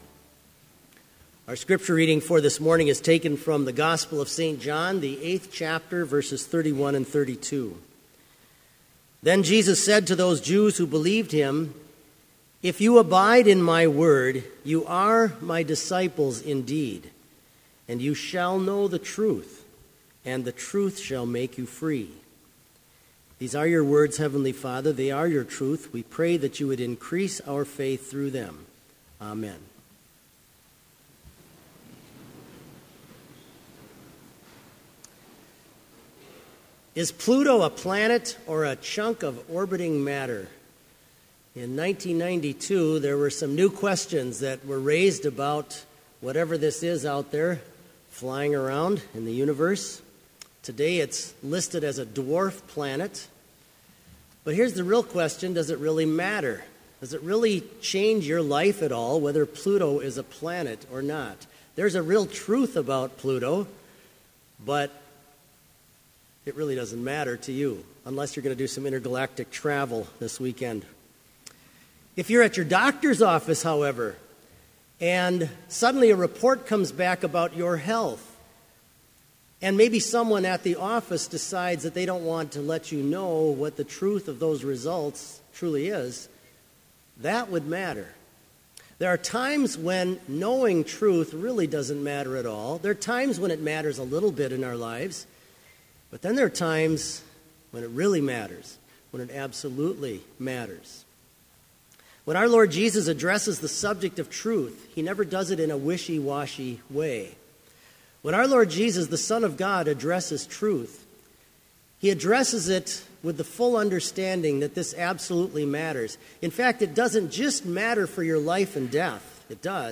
Complete service audio for Chapel - October 30, 2015